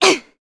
Hilda-Vox_Attack3_jp.wav